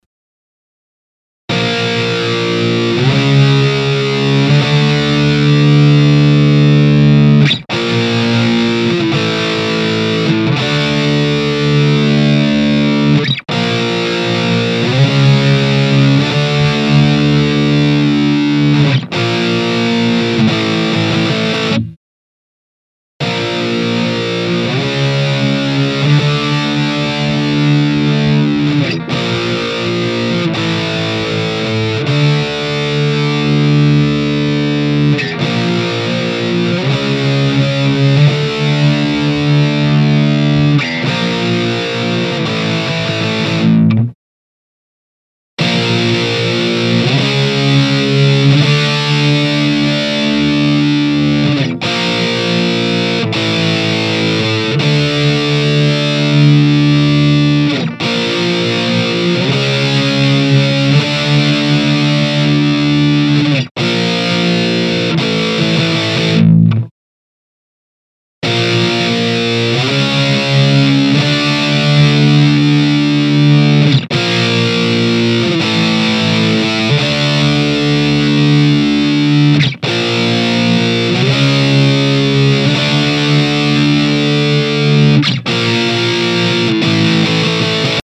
今までSHUREのSM57とAUDIX I5をピックアップを変えて比較。
AHB-1とEMG89でのサンプル。
EMG89は低音域のパンチとクリーントーンが綺麗なのでずっと使ってますよ。
インターフェースはPreSonus Audio BOX22VSLです。